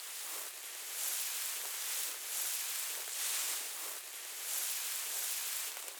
SFX / Character / Grass